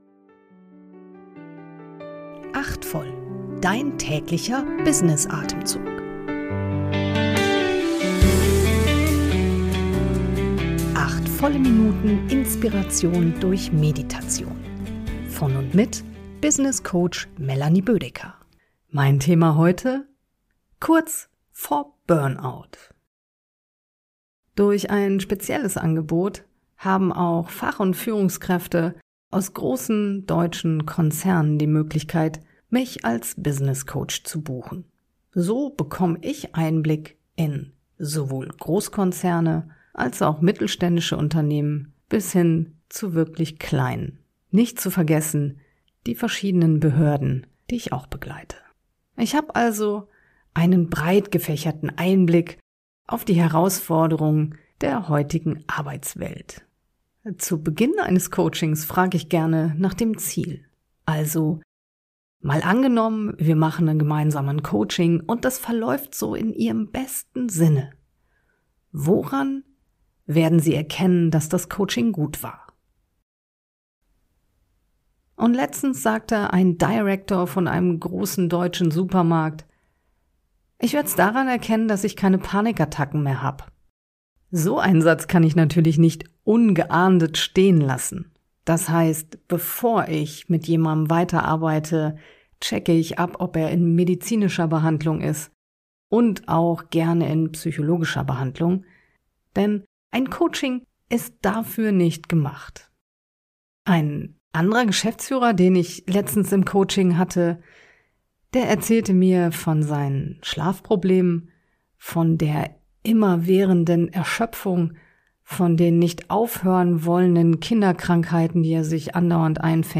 geleitete Kurz-Meditation.